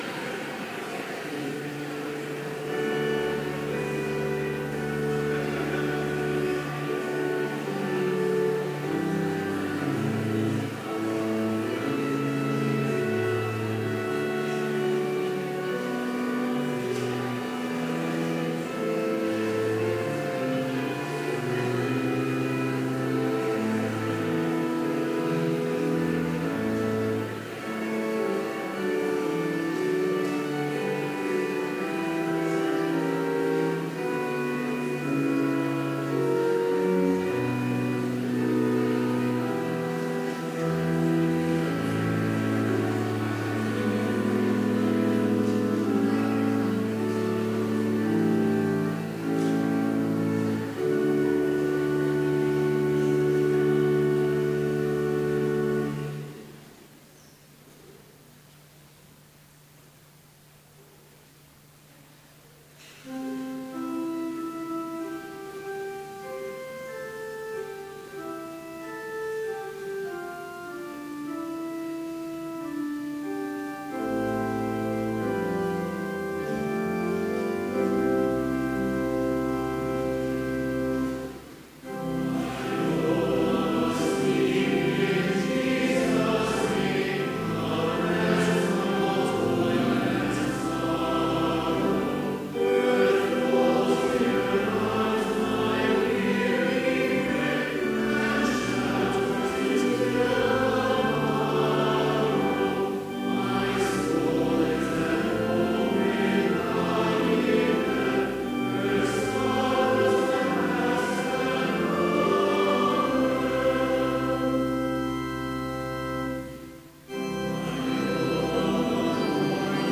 Complete service audio for Chapel - September 16, 2016